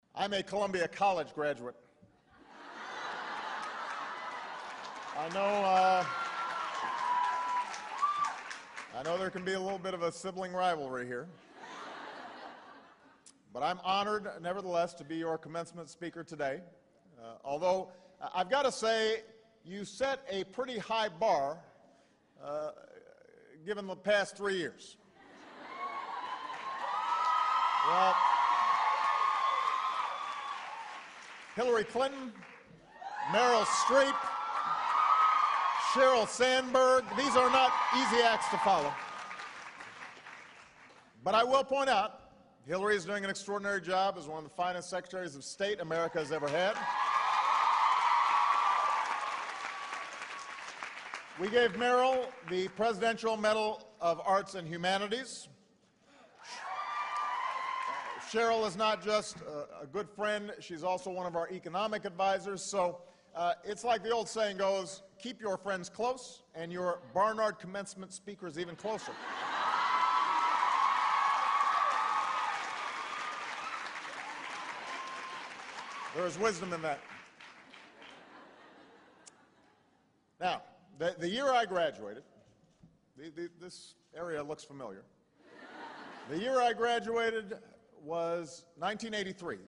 公众人物毕业演讲 第384期:奥巴马2012年哥伦比亚大学毕业演讲(2) 听力文件下载—在线英语听力室